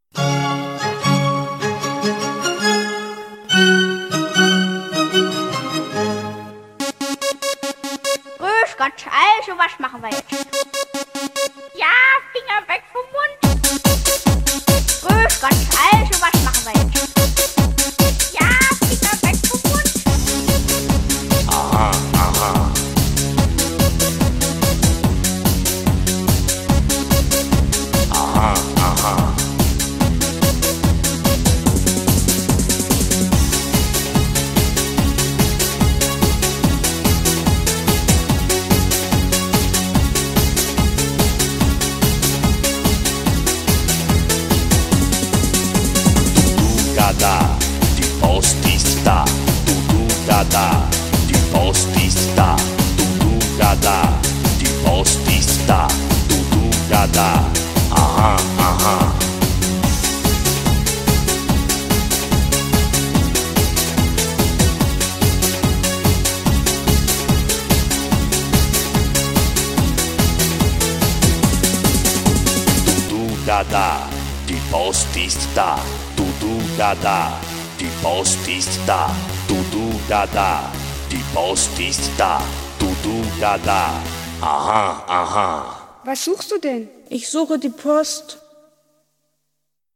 BPM145--1
Audio QualityMusic Cut
- Music from custom cut